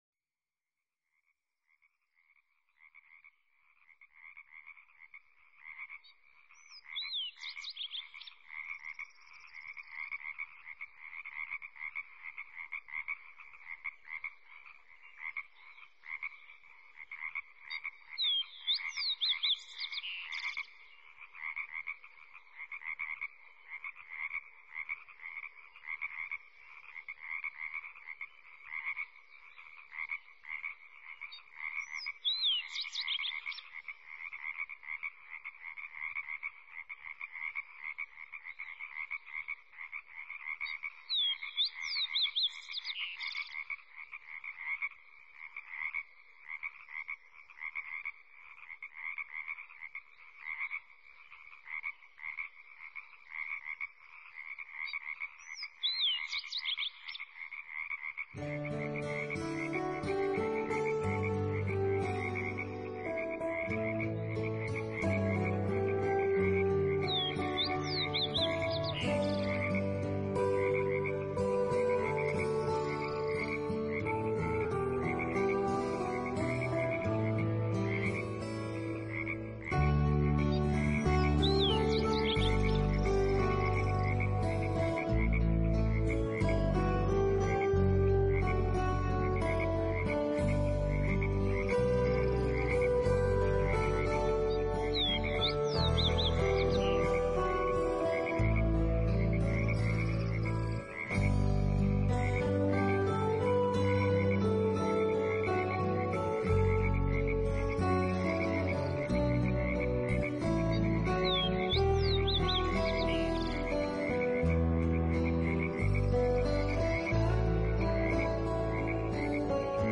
【新世纪纯音乐】
生物和自然声息始终铺满其上，音乐若有若无，象鸟儿时而展翅，时而滑翔。
静静的音乐，怯怯的弹奏。